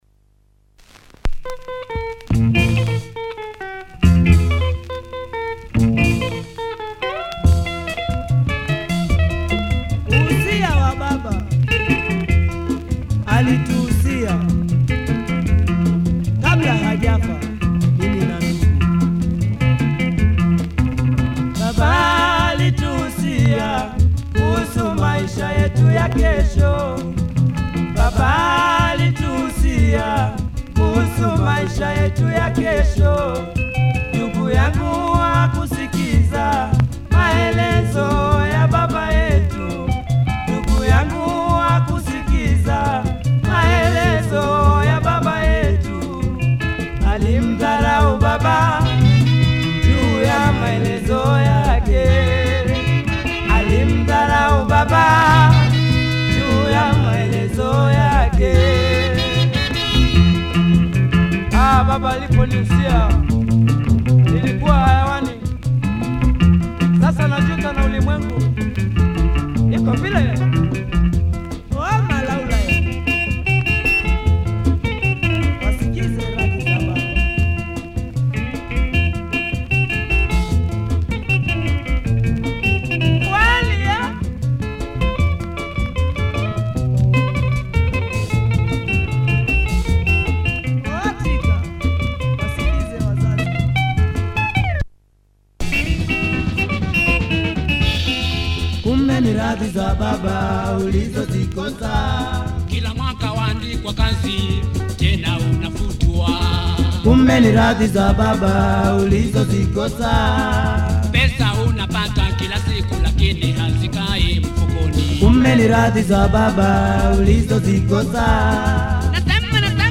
Nice Tanzania soukouss, check audio of both sides. https